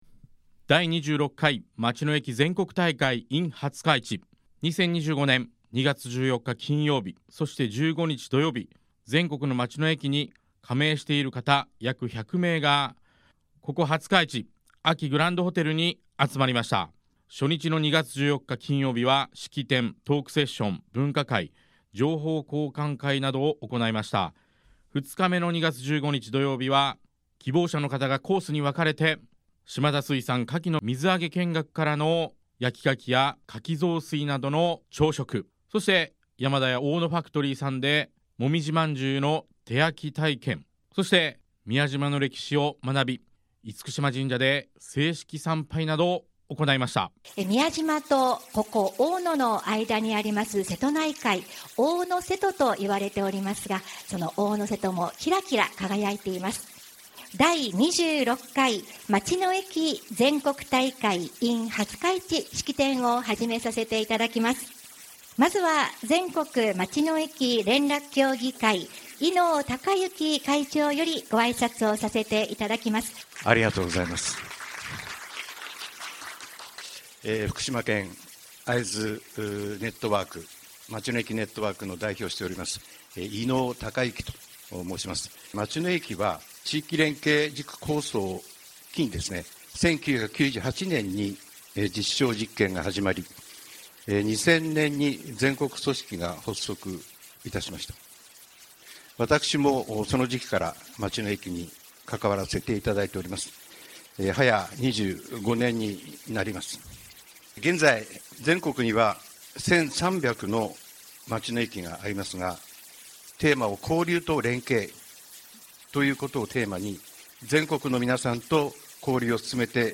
番組では、2日間の全国大会の模様と参加してくださった方にインタビューも。